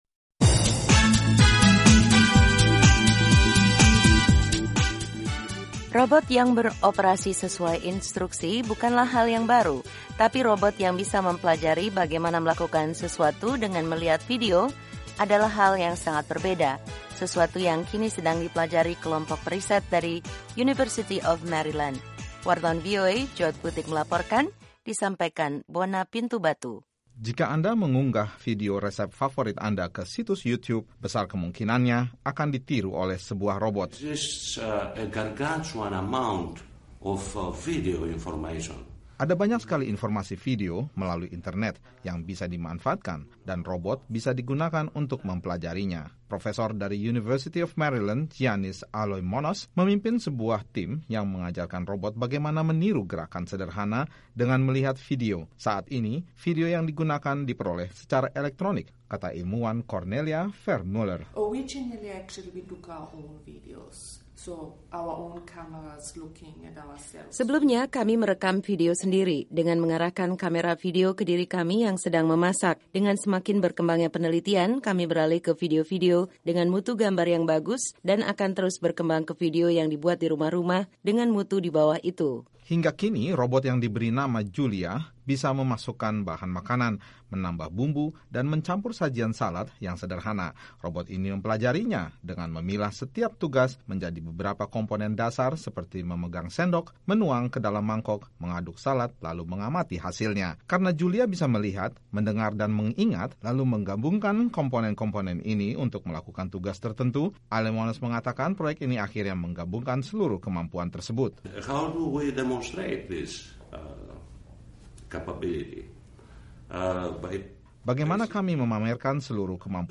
berita terkini seputar iptek dan kesehatan